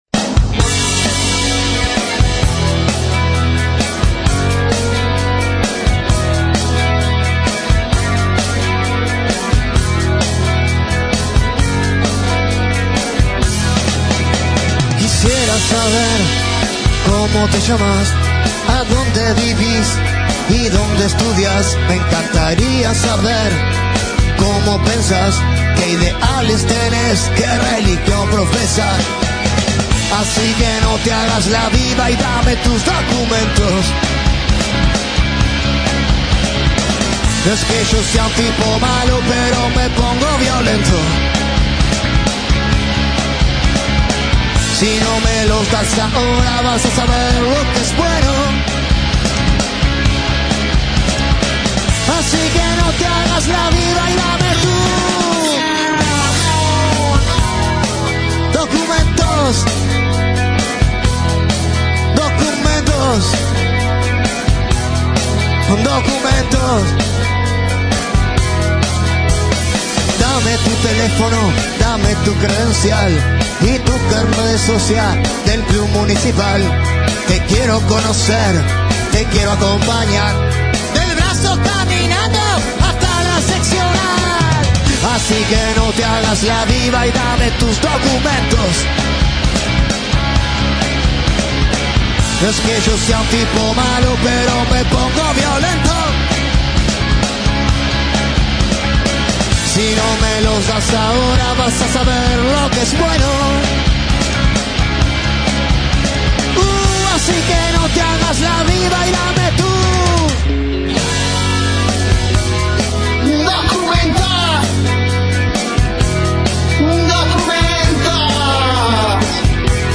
Los Tontos se hicieron presente en las entrevistas de rock al rock experience.